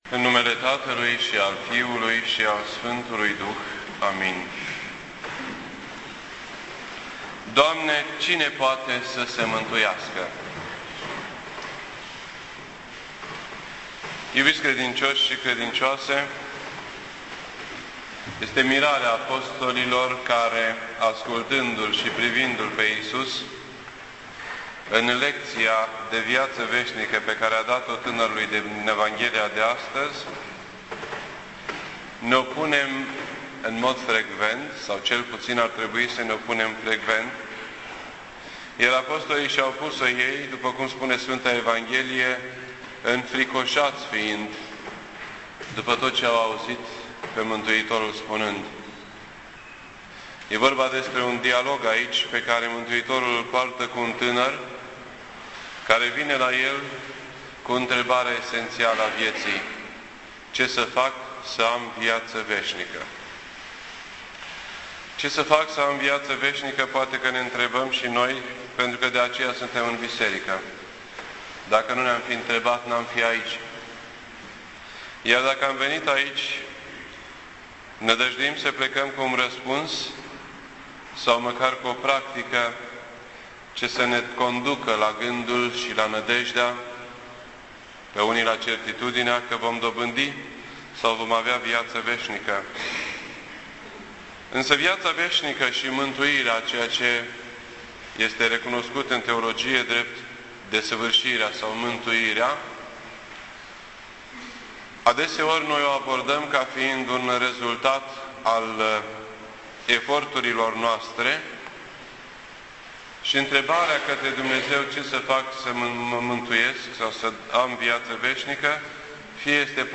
This entry was posted on Sunday, August 15th, 2010 at 9:08 PM and is filed under Predici ortodoxe in format audio.